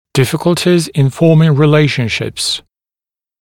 [‘dɪfɪk(ə)ltɪz ɪn ‘fɔːmɪŋ rɪ’leɪʃ(ə)nʃɪps][‘дифик(э)лтиз ин ‘фо:мин ри’лэйш(э)ншипс]трудности в формировании отношений